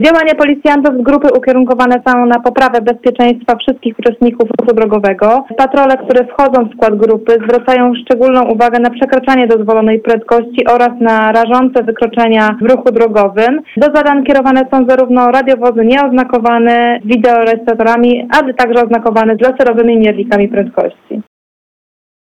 Mówi podkom.